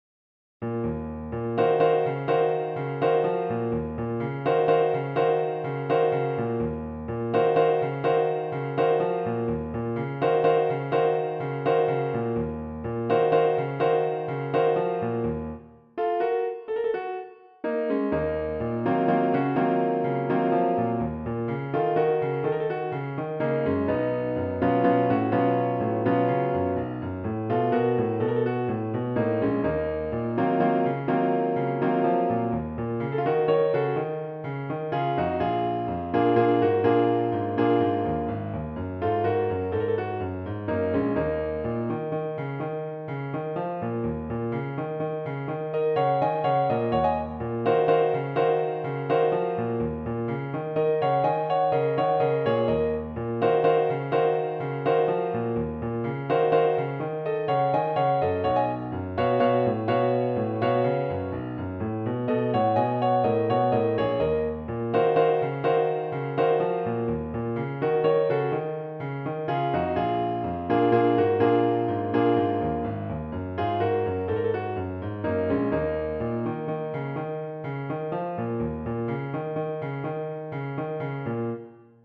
especially for solo piano
is in the key of Eb minor
The form is I – VI – I – V – IV – I.